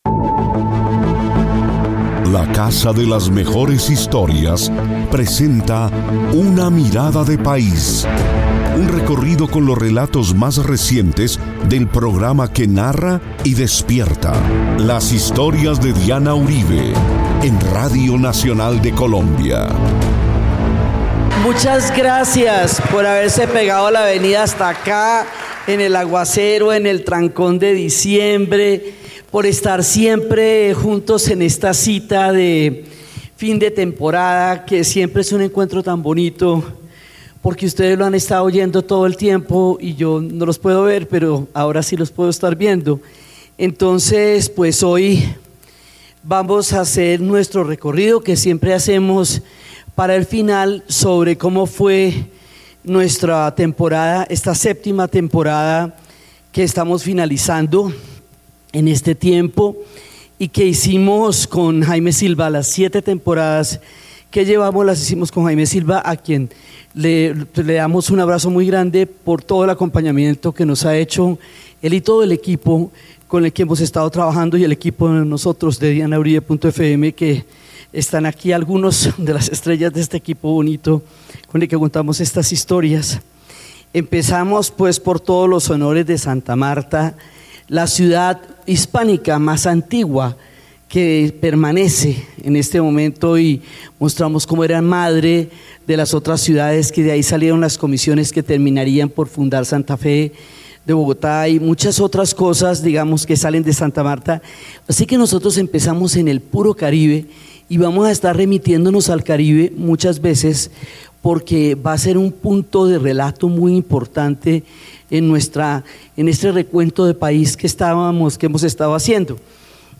Desde la Biblioteca Virgilio Barco de Bogotá, Diana Uribe recorrió los hechos y personajes más destacados de la temporada: la sensación que provocó la tragedia de Armero en 1985 y lo que perdió Colombia con la trágica muerte de Jaime Garzón.